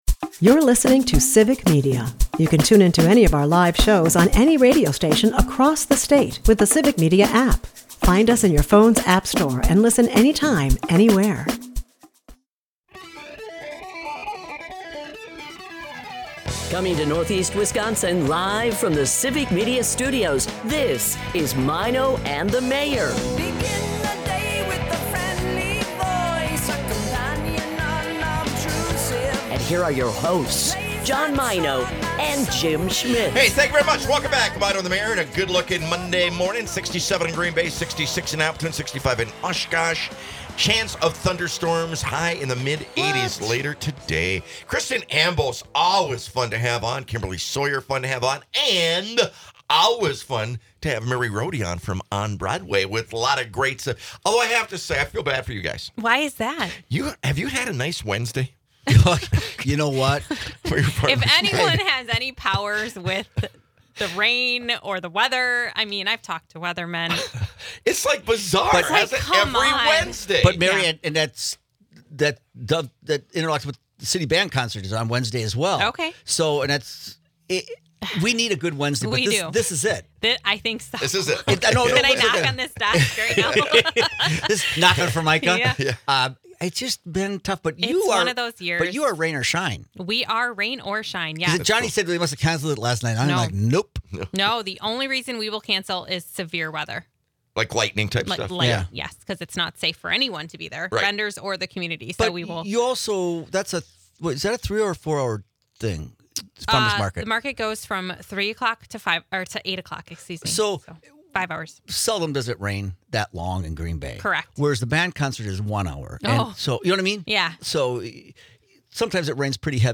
A lively chat unfolds about Green Bay's biggest annual event, the Festival Foods Fire Over the Fox, famed for its spectacular fireworks and diverse music stages.
Side trails into local traditions, the legality of fireworks, and pasties for breakfast keep the energy high and the laughs rolling.